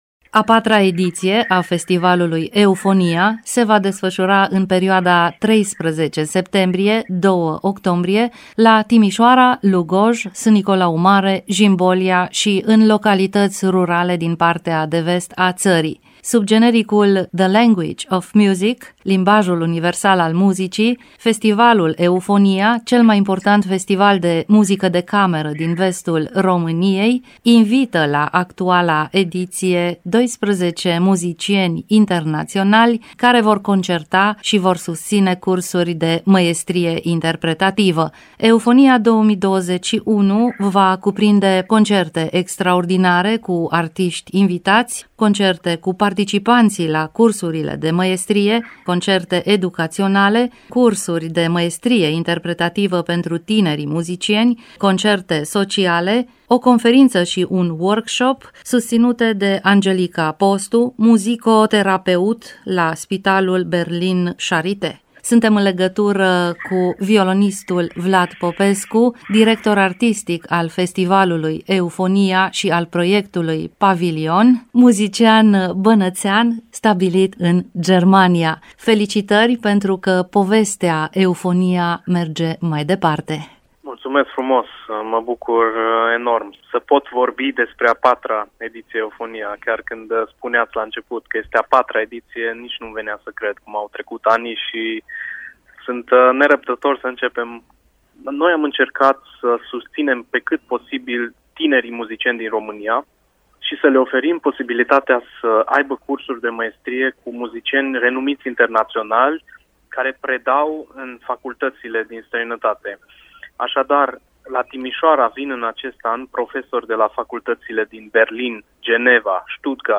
Detalii despre actuala ediţie, în dialogul